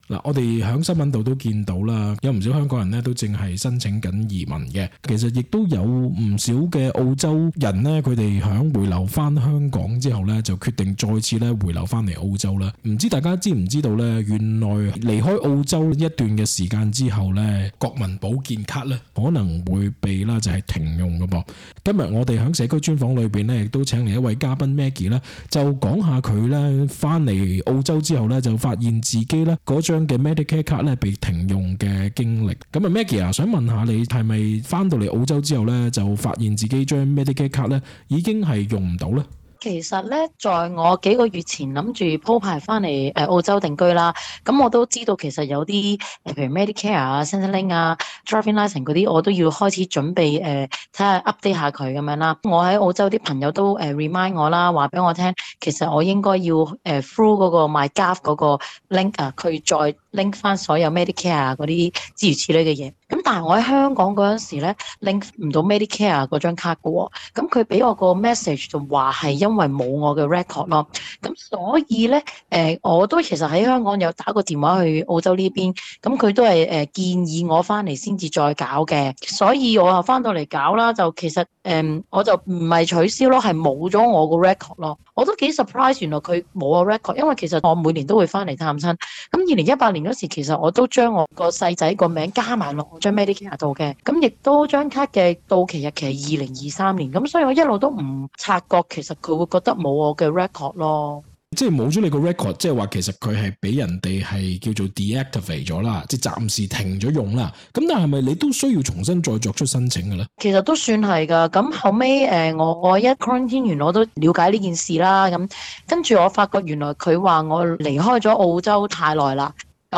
community_interview_medicare_deactivation_podcast.mp3